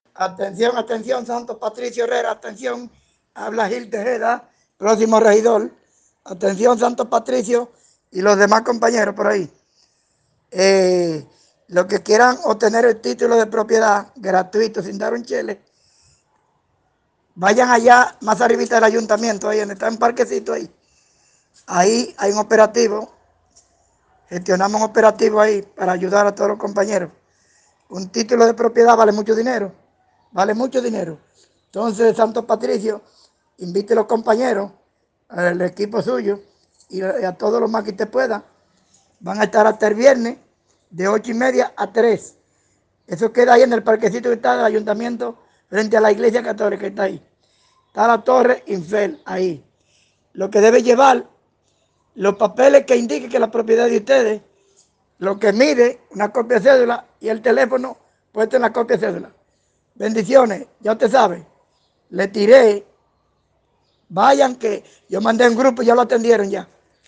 Como se puede escuchar en los mensajes, una persona, quien se identifica como aspirante a regidor por SDO para los próximos comicios electorales, refiere que estarán recibiendo documentación de los propietarios en un punto especifico, cerca del Ayuntamiento, razon pr la que muchos moradores están yendo con sus documentos y una inmensa multitud ya ha sido atendida, algunos han dicho que les han solicitado dinero para el proceso de validación de sus títulos.